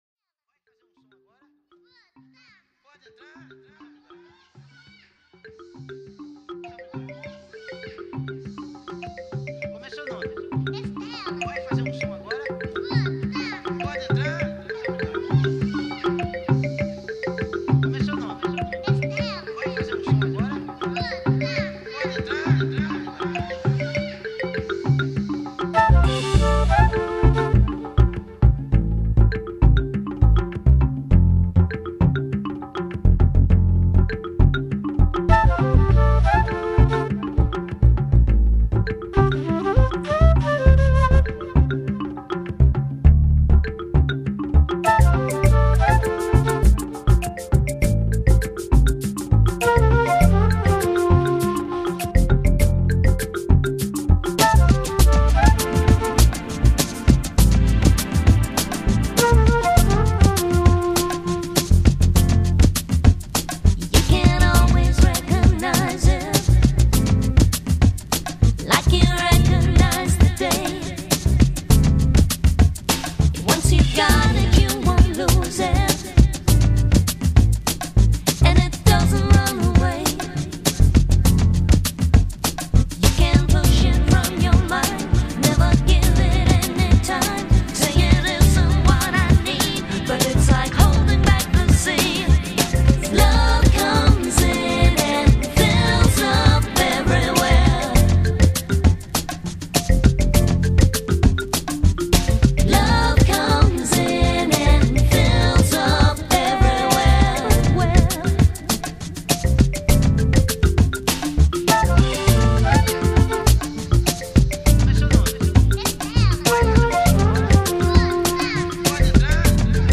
Lo-Fi, ChillOut